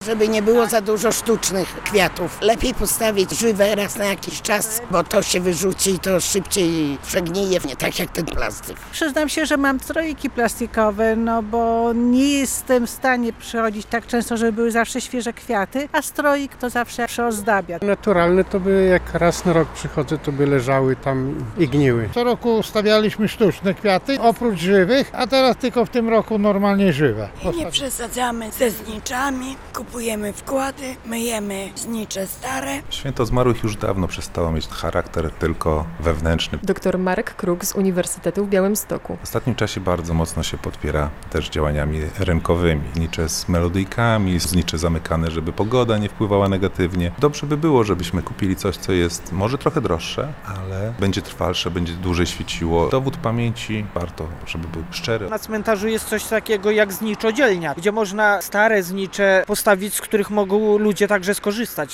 Najlepiej, żeby to były żywe kwiaty. Zwracamy uwagę na stroiki z naturalnych roślin, ostatnio widzieliśmy z szyszek, bardzo fajne motywy, choć jeszcze trudno dostępne - mówią odwiedzający groby bliskich mieszkańcy.